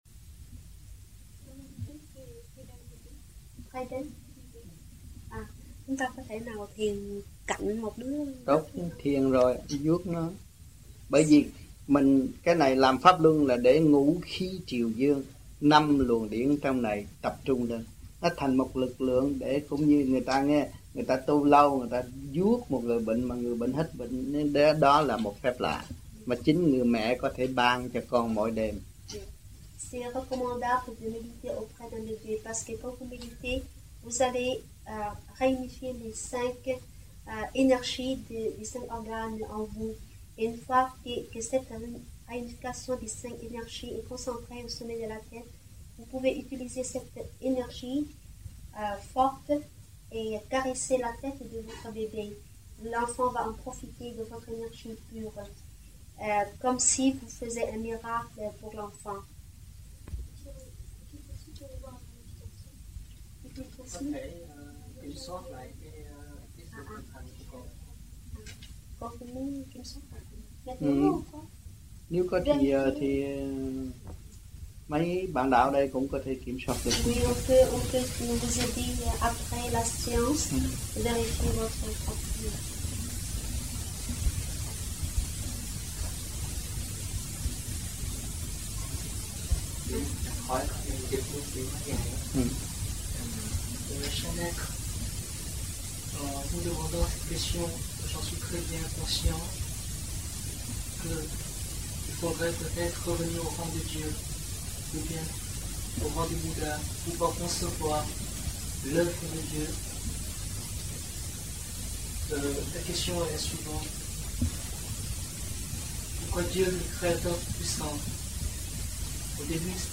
1982-12-12 - MARSEILLE - THUYẾT PHÁP 05